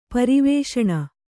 ♪ pari vēṣaṇa